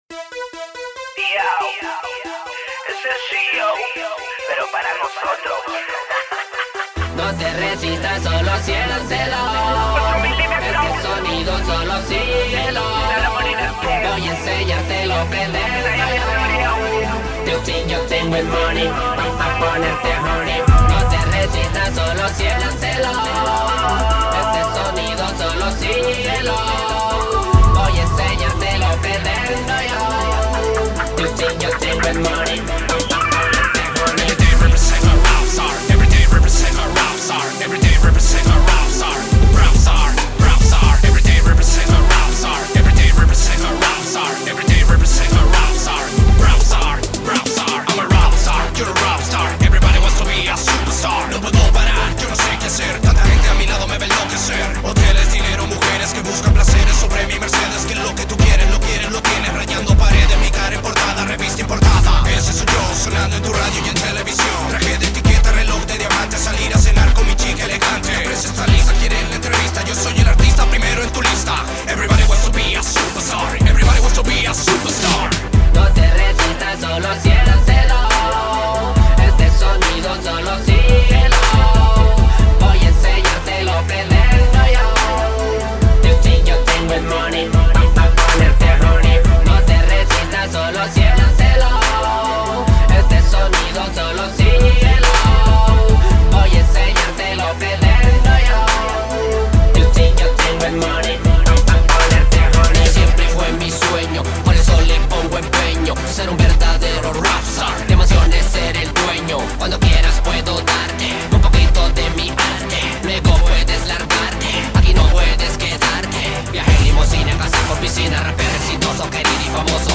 Voces urbanas